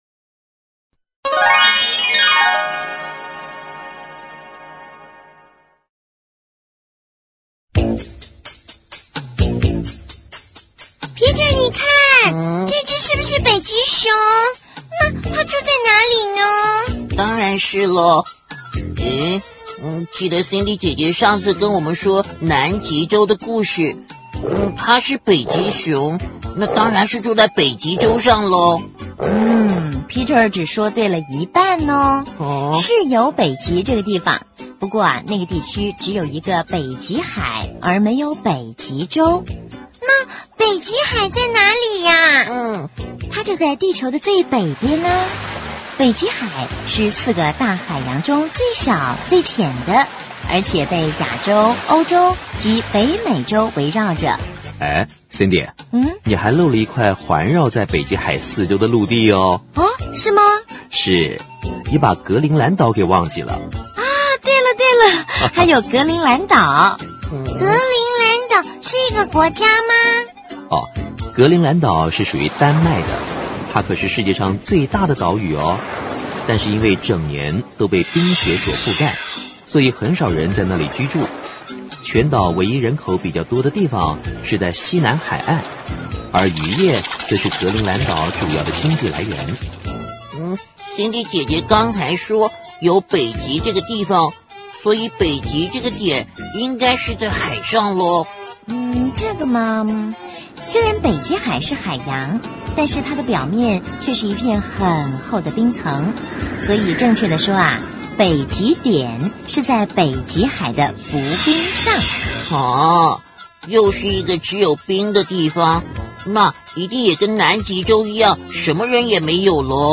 首页>mp3 > 儿童故事 > 北极海